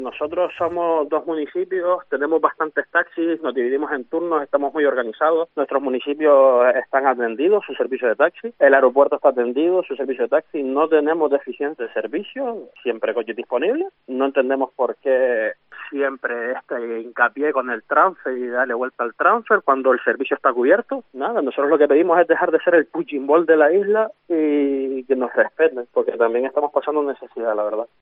Así se pronunciaba en los micrófonos de COPE Canarias